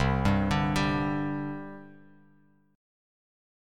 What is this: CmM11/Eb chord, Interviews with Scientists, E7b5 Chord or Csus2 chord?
Csus2 chord